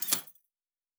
Locker 8.wav